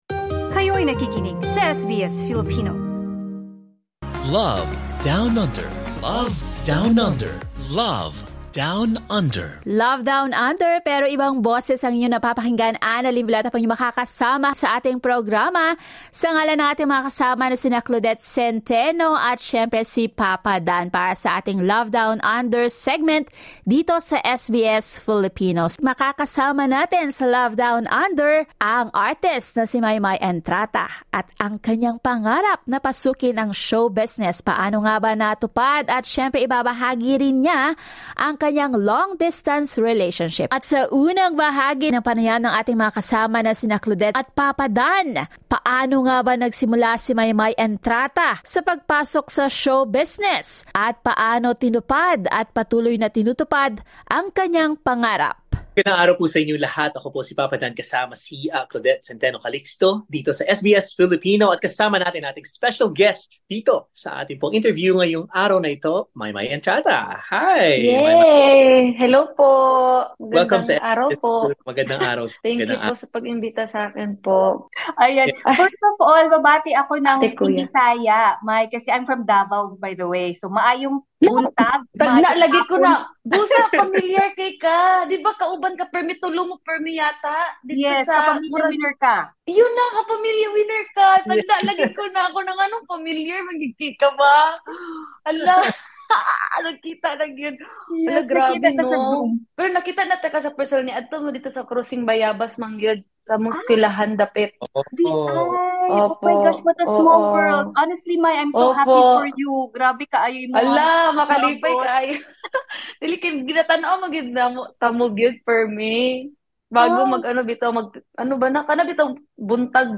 LISTEN TO THE PODCAST Maymay Entrata on keeping LDR strong 22:25 In an interview with SBS Filipino, Maymay Entrata shares her journey entering the Philippine entertainment industry after several tries to join and fail a number of television reality shows.